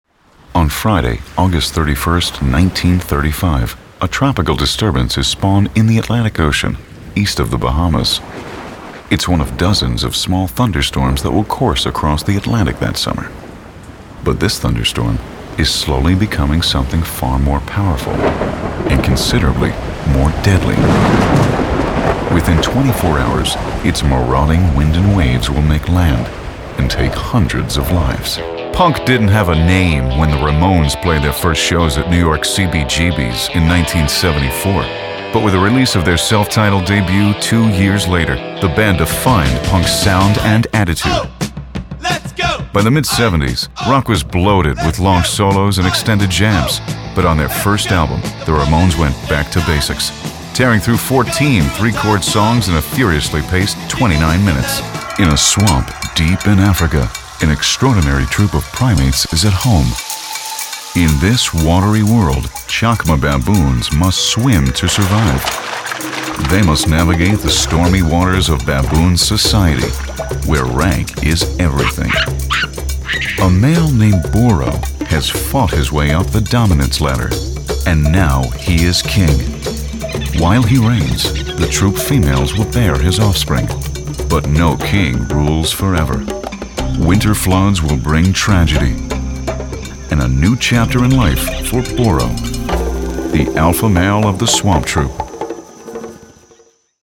narration2009.mp3